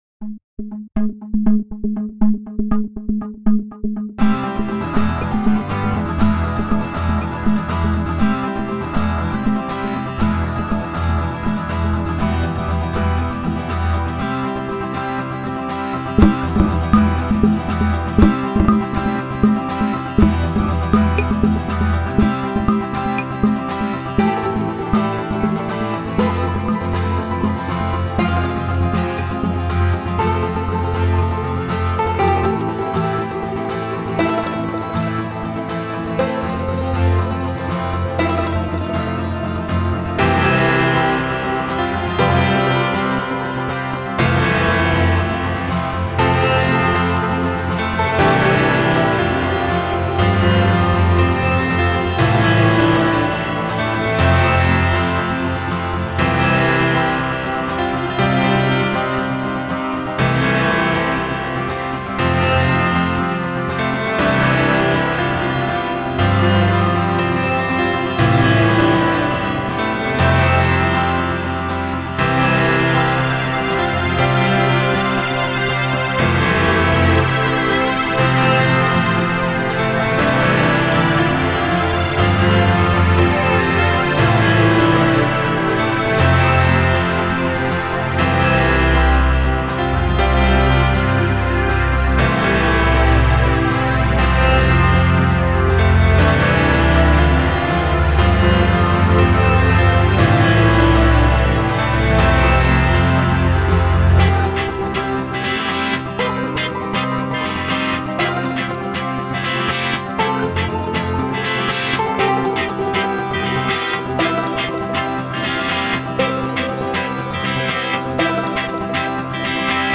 Der Gesang fehlt.